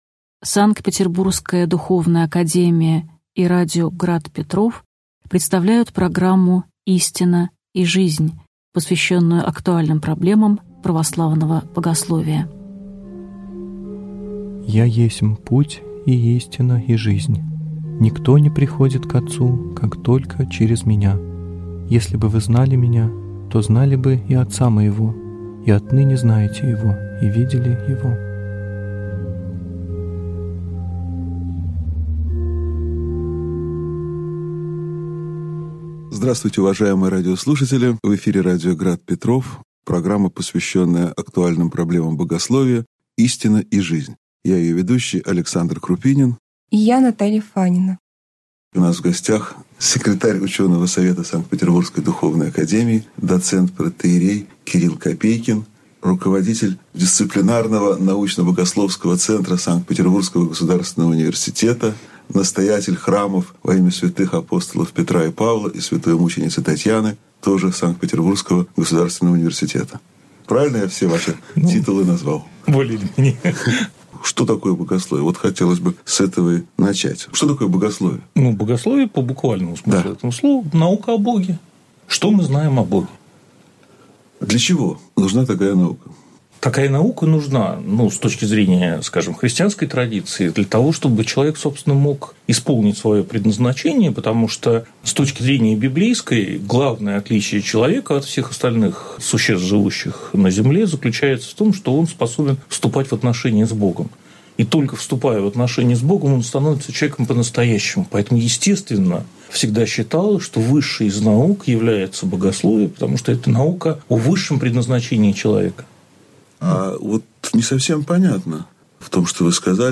Аудиокнига Истина и Жизнь (часть 1) | Библиотека аудиокниг